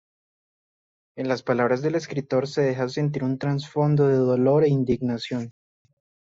Pronounced as (IPA) /doˈloɾ/